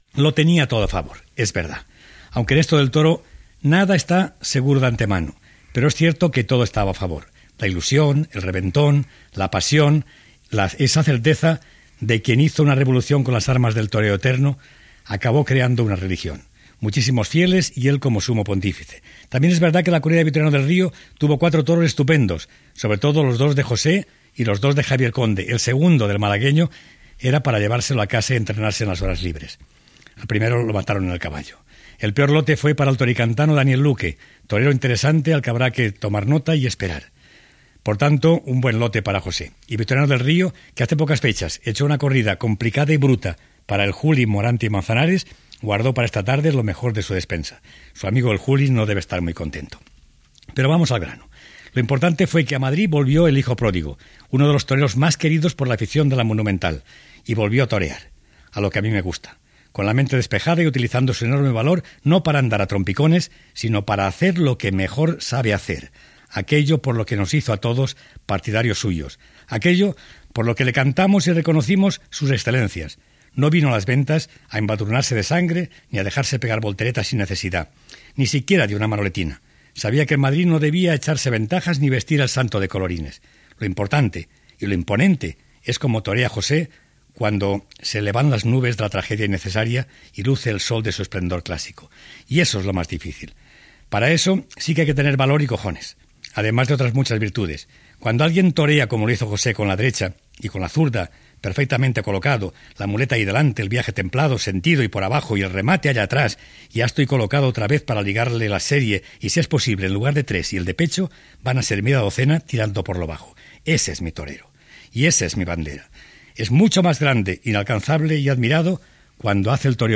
Editorial sobre el retorn del torero José Tomàs a la plaça de Las Ventas de Madrid, després de sis anys, i sintonia cantada del programa
Informatiu